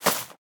1.21.5 / assets / minecraft / sounds / block / vine / climb2.ogg
climb2.ogg